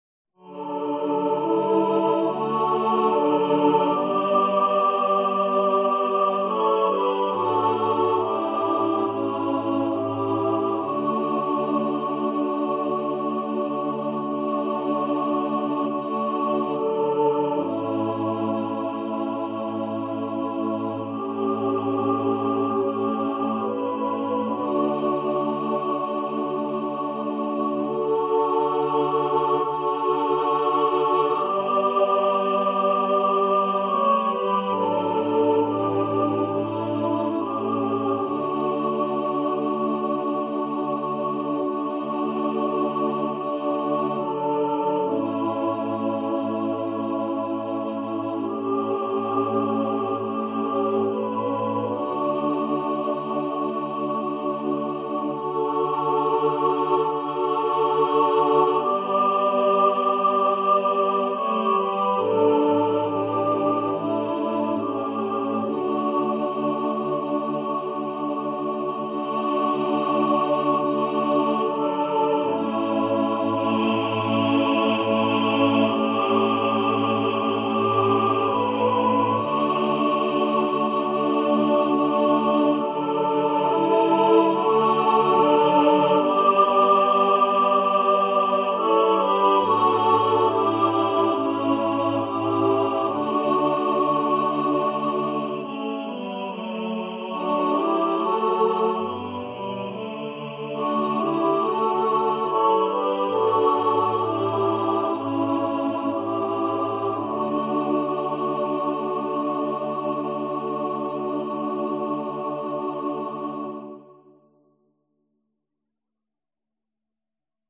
Leicht und wirkungsvoll, a cappella zu singen.